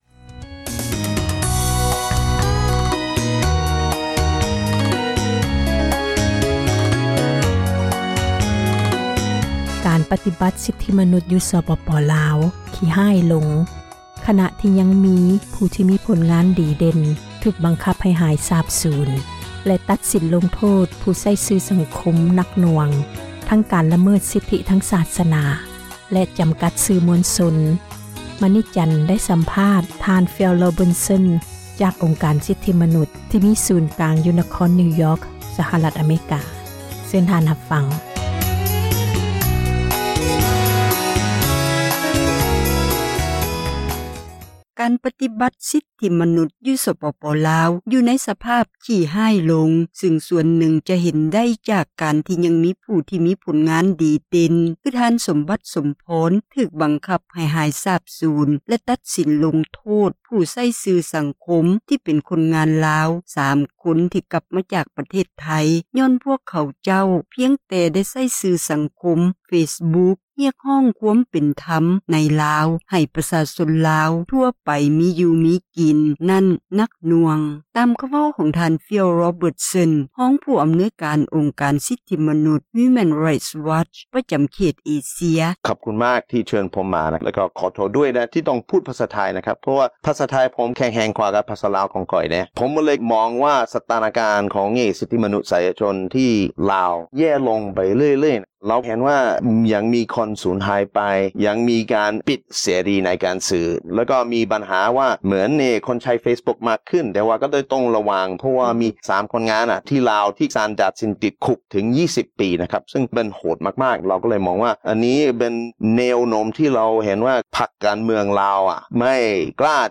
ການສັມພາດ
ທີ່ສໍານັກງານ ໃຫຍ່ RFA ນະຄອນຫລວງ Washington DC ສະຫະຣັຖ ອະເມຣິກາ.